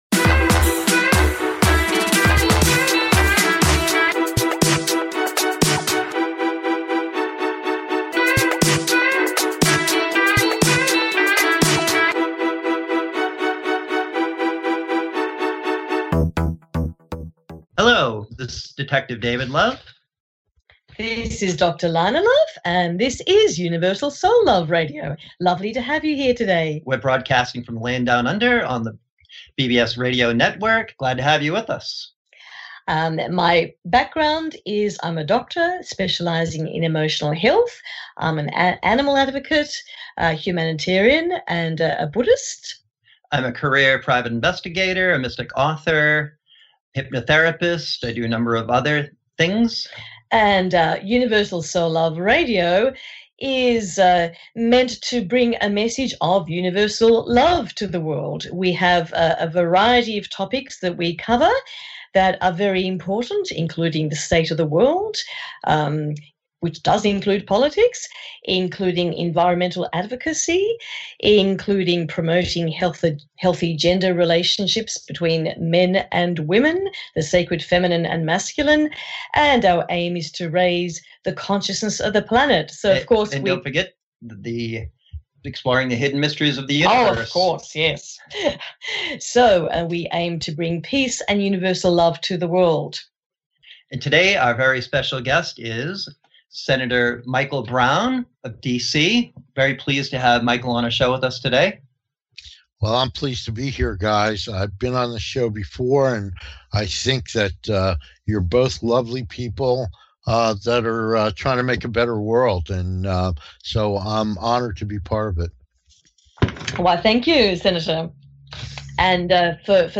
Talk Show Episode
with Senator Michael D Brown